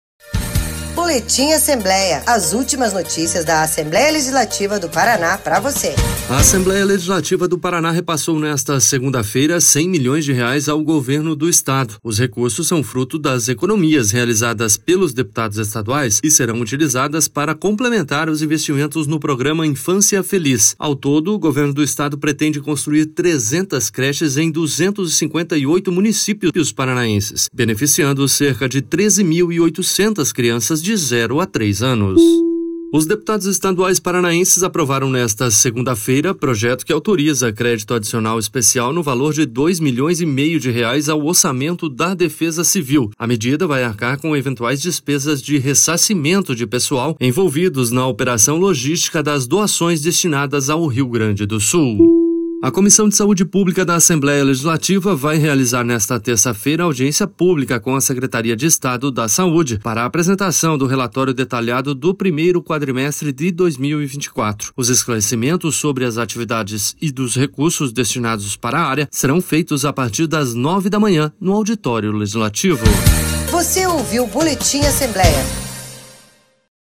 Confira em áudio um resumo das principais notícias desta segunda-feira, 10 de junho, no Boletim Assembleia.